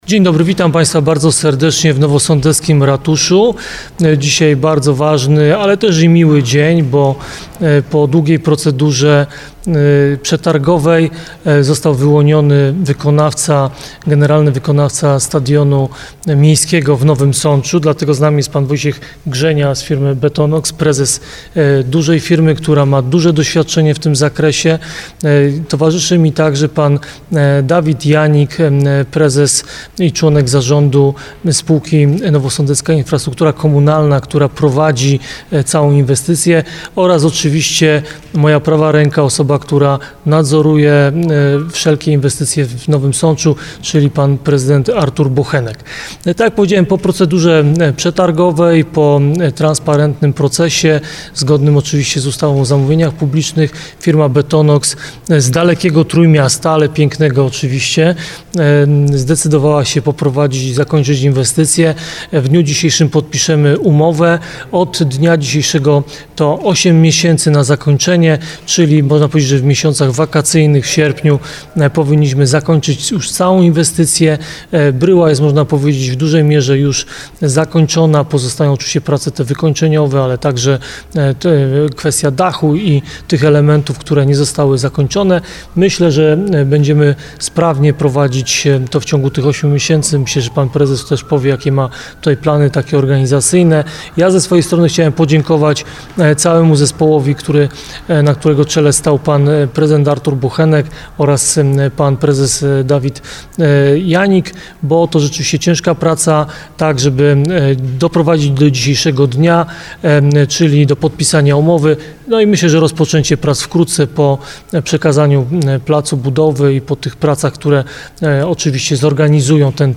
Posłuchaj konferencji prasowej: https